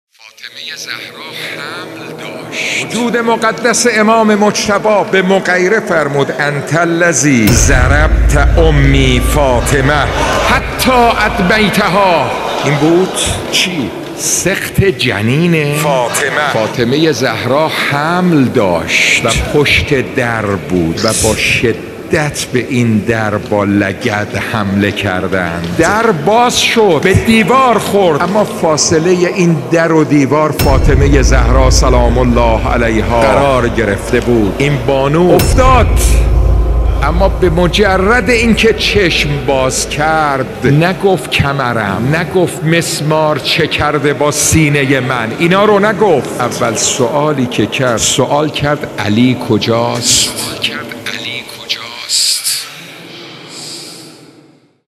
سخنرانی آیت الله بروجردی| دفتر آیت الله علوی بروجردی |فاطمیه 1441